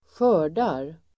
Uttal: [²sj'ö:r_dar]